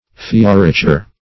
Search Result for " fioriture" : The Collaborative International Dictionary of English v.0.48: Fioriture \Fio`ri*tu"re\, n. pl.